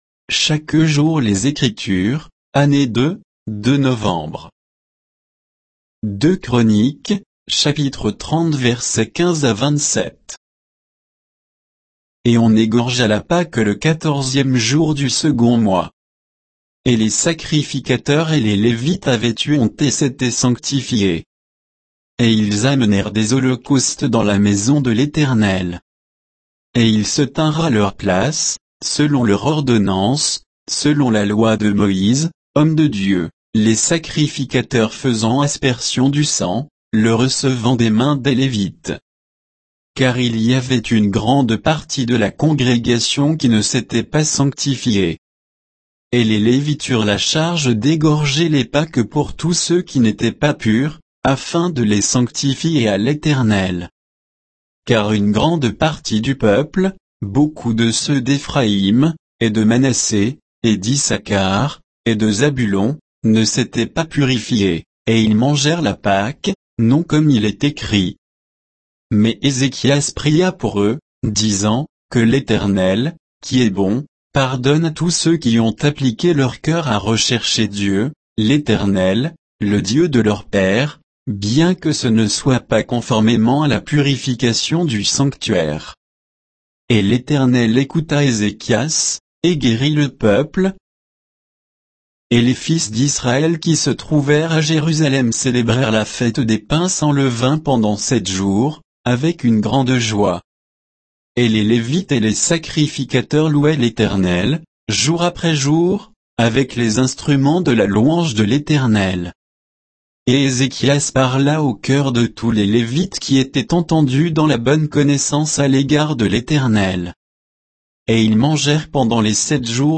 Méditation quoditienne de Chaque jour les Écritures sur 2 Chroniques 30, 15 à 27